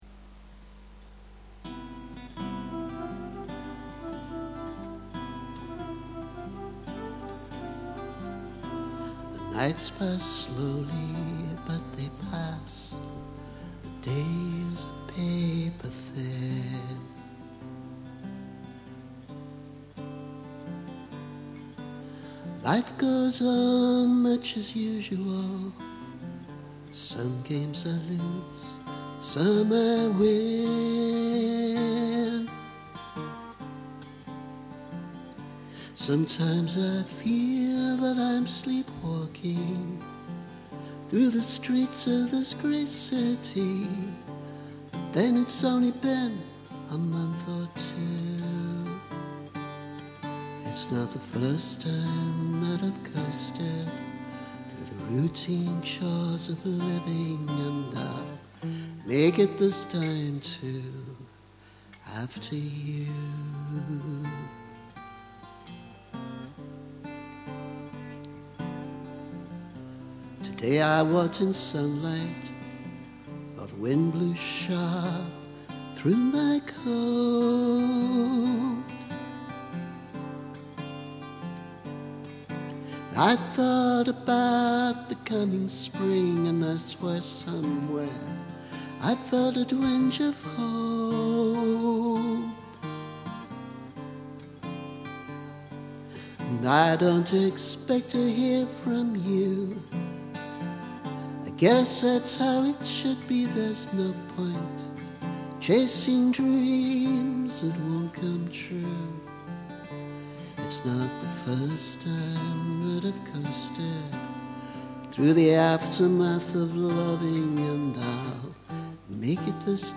A slightly jazzy demo.